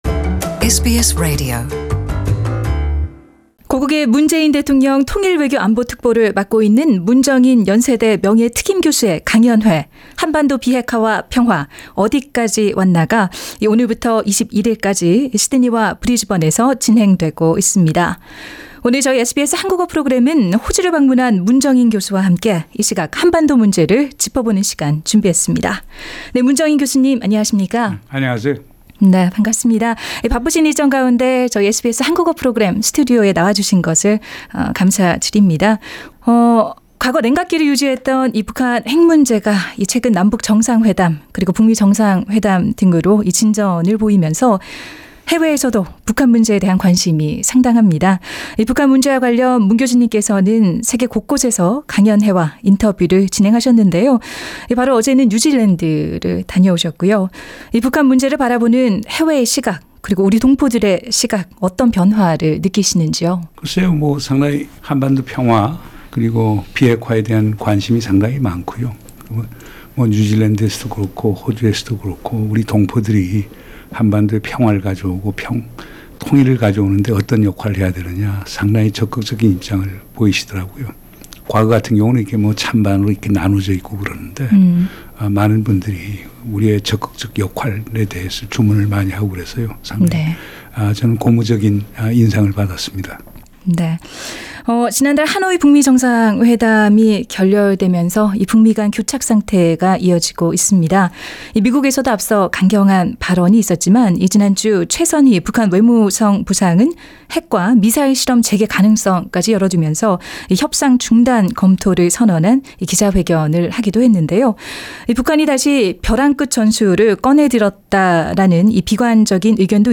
[특별대담] 문정인 특보가 바라본 '한반도 해법'